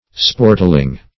sportling - definition of sportling - synonyms, pronunciation, spelling from Free Dictionary
Search Result for " sportling" : The Collaborative International Dictionary of English v.0.48: Sportling \Sport"ling\ (-l[i^]ng), n. A little person or creature engaged in sports or in play.